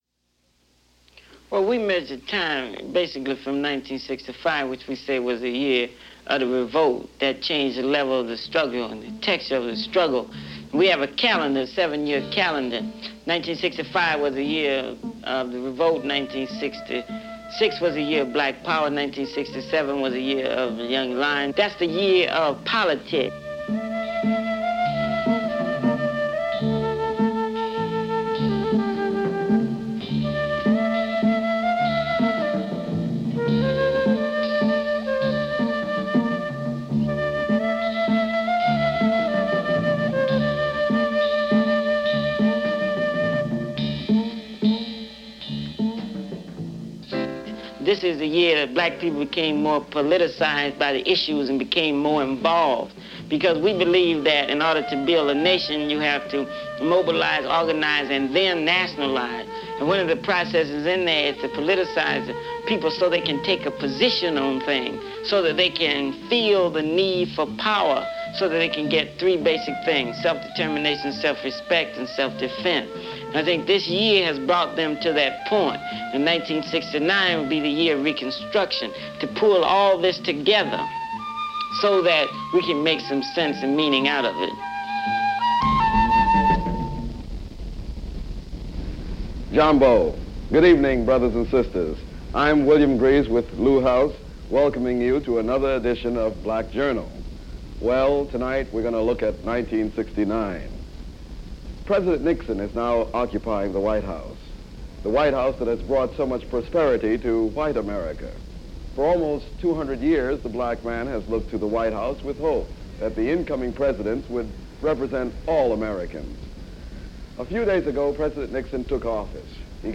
Report Card On Black America - 1968 - Black Journal - January 27, 1969 - Documentary/News program on issues in Black America and Civil Rights.
This program, a monthly news feature of the pre-PBS NET Public Broadcasting network, looks at what transpired during the pivotal year of 1968; a year that had high hopes and stark realities for Black America.